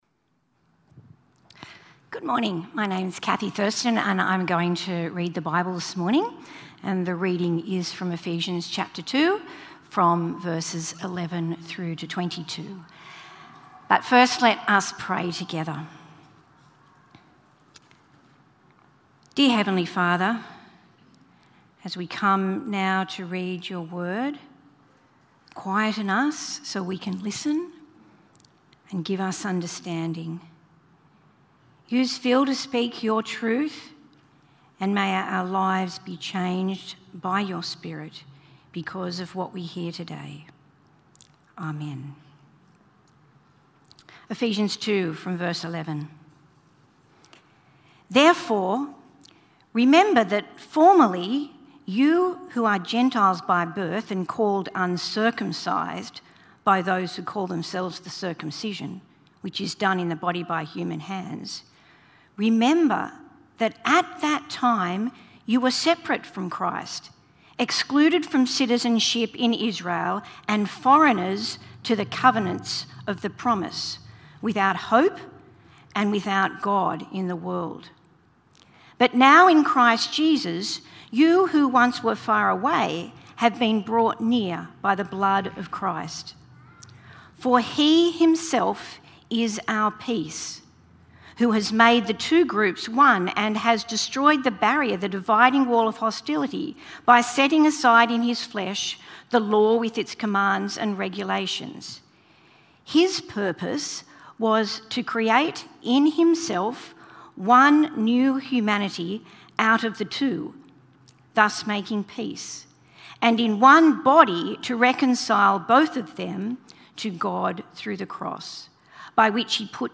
Talk5-ReconciledByChrist.mp3